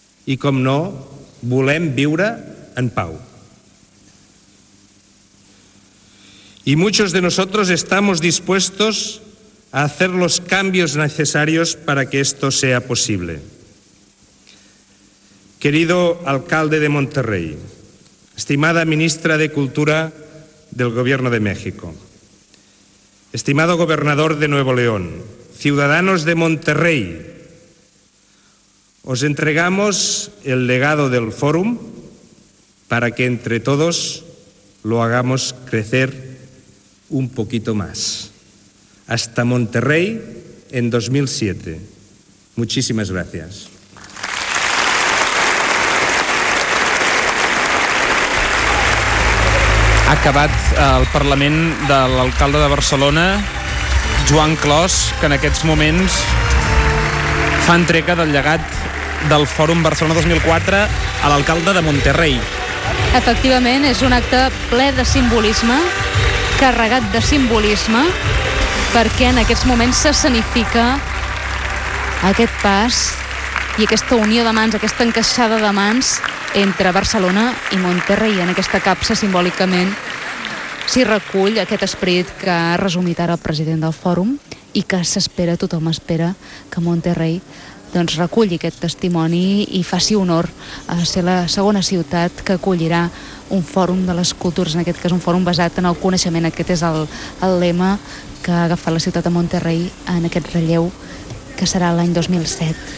Paraules de Jordi Hereu, alcalde de Barcelona, dirigides a l'alcalde de Monterrey (Mèxic), ciutat que seria seu del Fòrum de les Cultures 2007.
Fragment extret de l'arxiu sonor de COM Ràdio